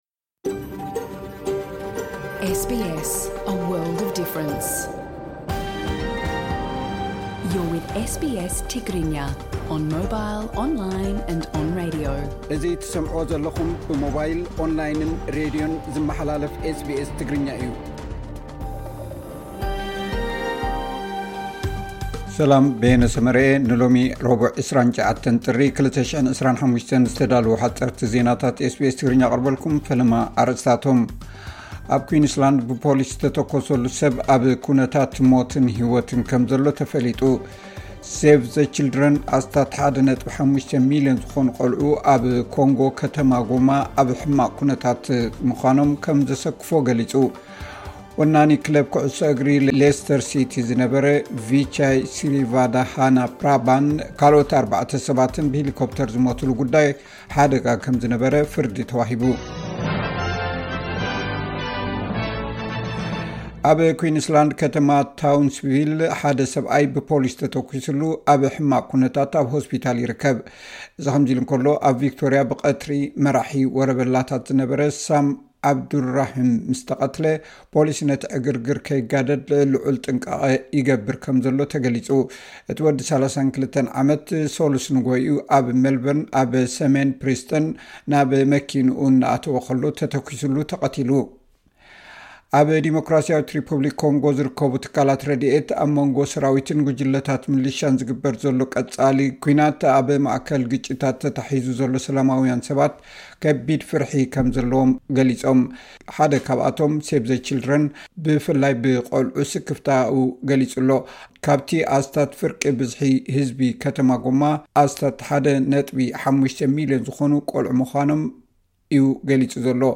ዕለታዊ ዜና ኤስ ቢ ኤስ ትግርኛ (29 ጥሪ 2025)